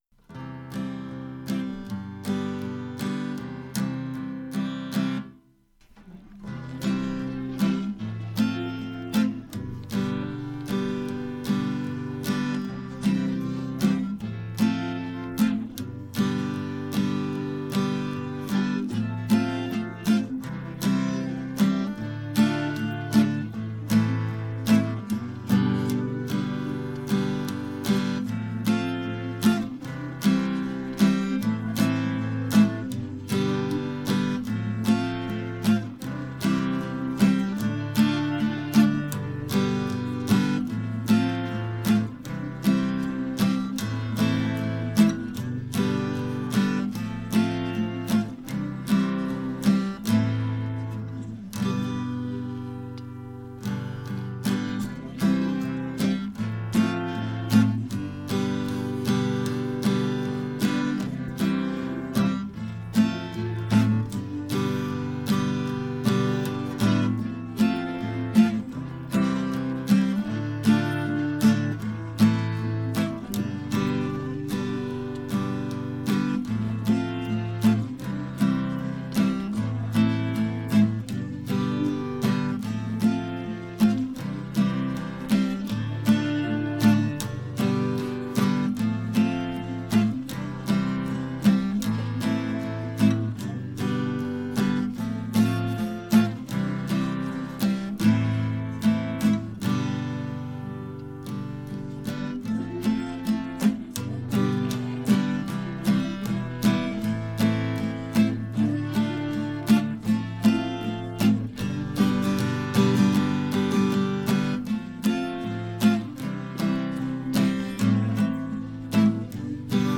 Übungsaufnahmen - Wiehnachtstied
Runterladen (Mit rechter Maustaste anklicken, Menübefehl auswählen)   Wiehnachtstied (Playback - Gitarre)
Wiehnachtstied__7_Playback_Gitarre.mp3